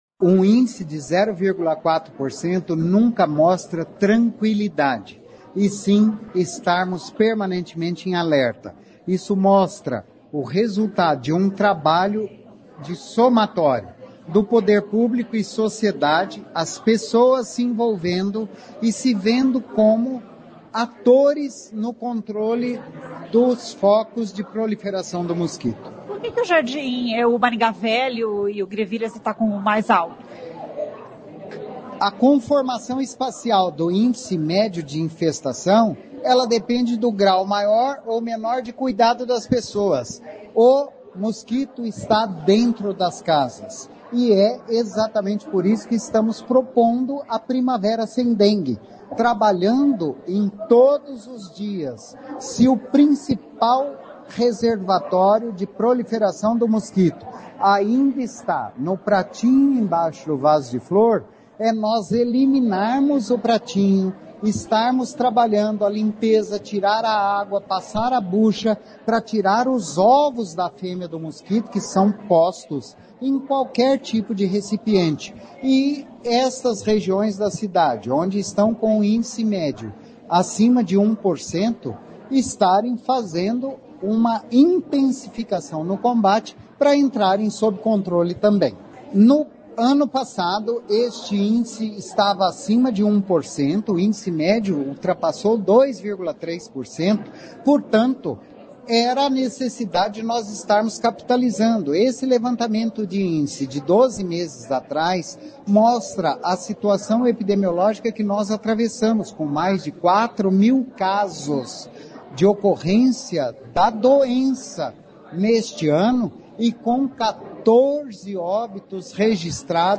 Ouça o que diz o secretário de Saúde, Antônio Carlos Nardi.